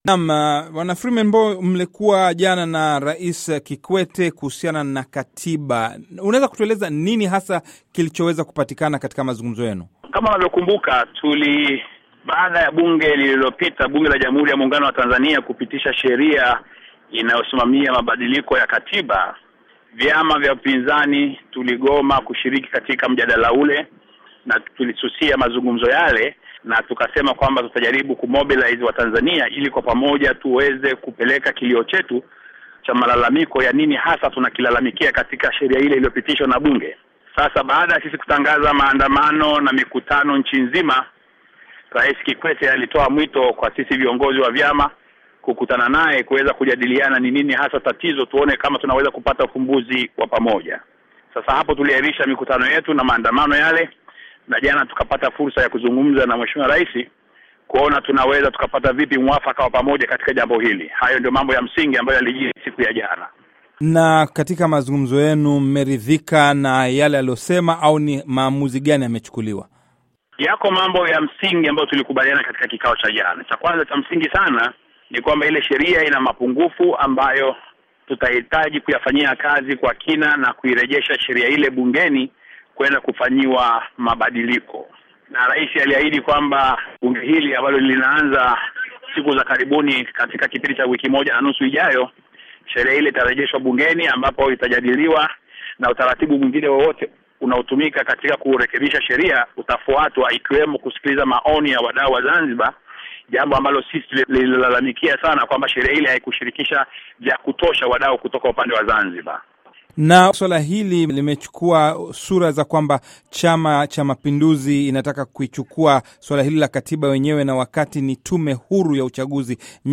Embed share Mahojiano na Mbowe by VOA Swahili – Idhaa ya Kiswahili ya Sauti ya Amerika Embed share The code has been copied to your clipboard.